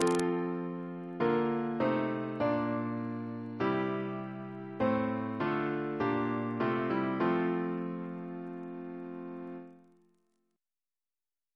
CCP: Chant sampler